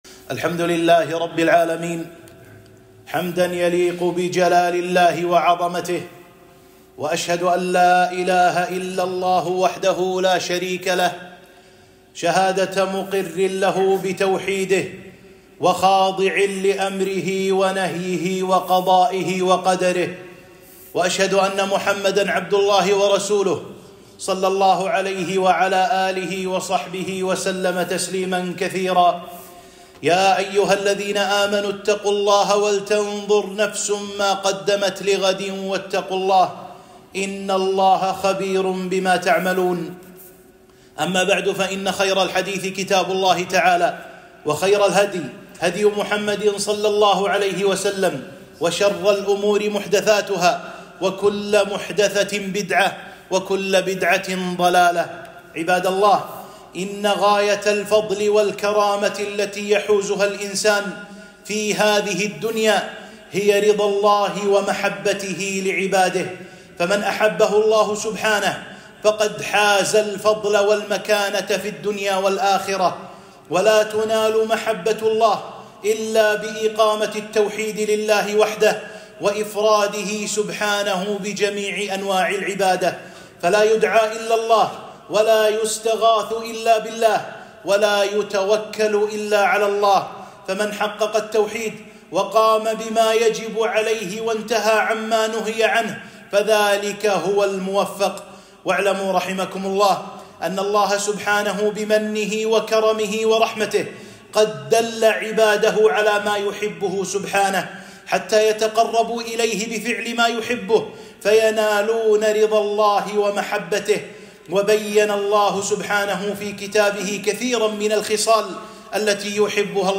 خطبة - أسباب الفوز بمحبة الله تعالى لعباده